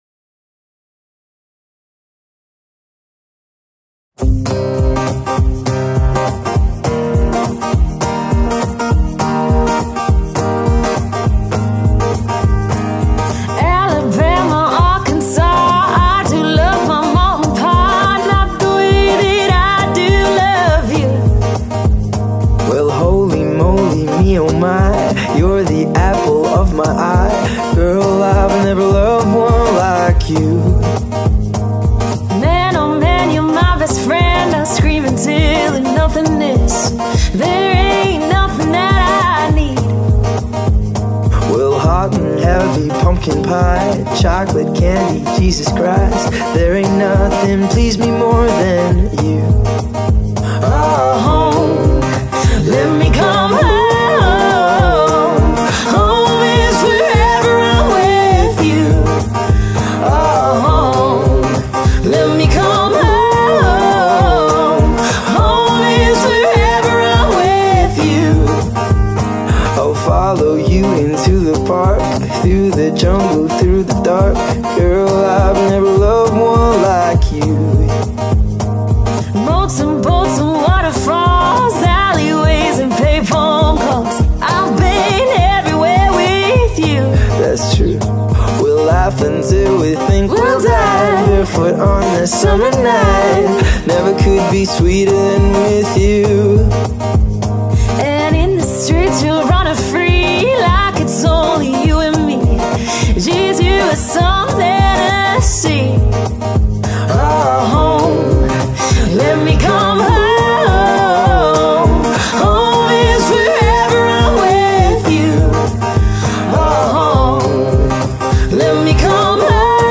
Dual Vocals | Guitar | Piano | Looping | DJ
energetic music experience